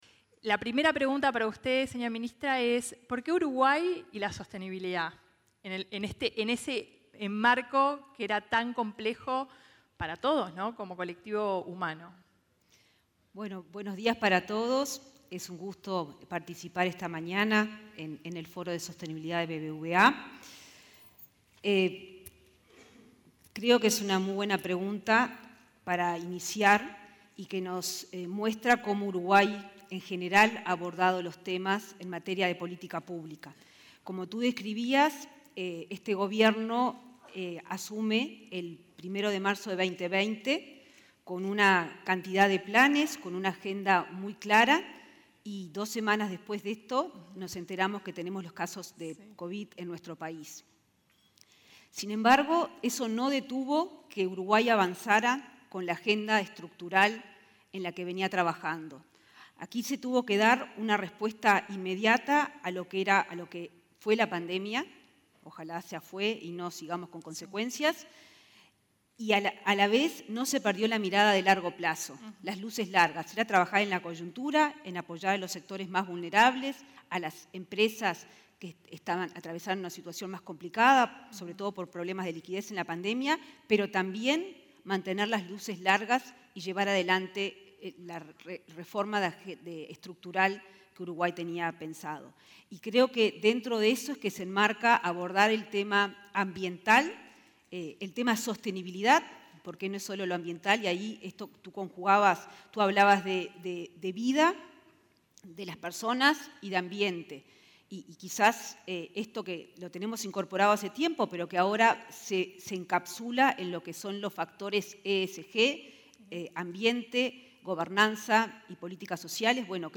Palabras de la ministra de Economía, Azucena Arbeleche
La ministra de Economía, Azucena Arbeleche, participó en un foro de sostenibilidad, organizado por el BBVA.